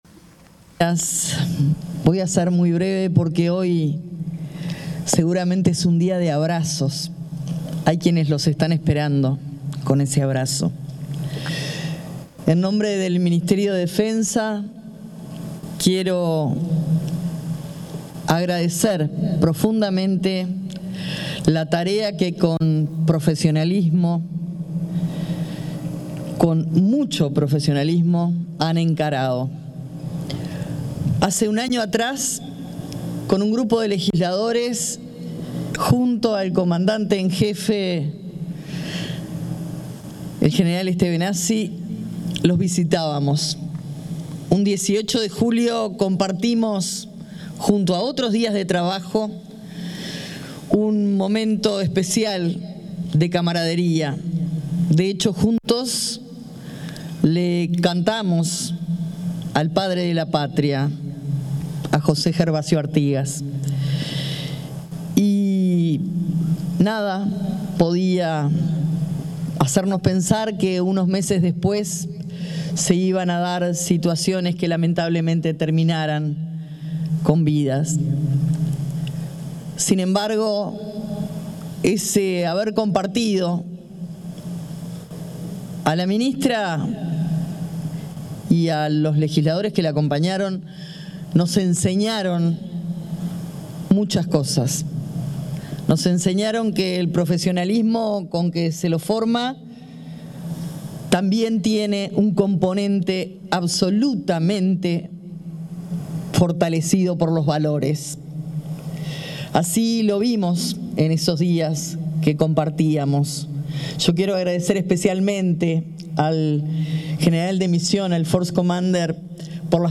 Palabra de autoridades en ceremonia de bienvenida a contingente que regresó del Congo
oratorias.mp3